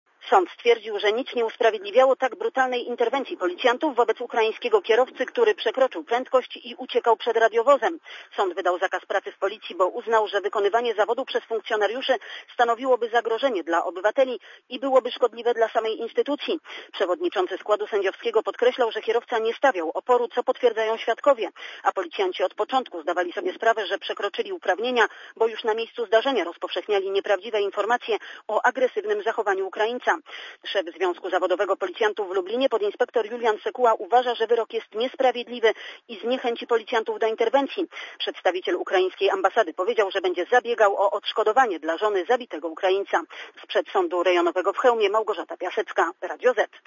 Relacja reporterki Radia Zet (190Kb)